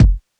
DJP_KICK_ (103).wav